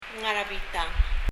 ngar er a bitang　　[ŋar ə bitʌŋ]
発音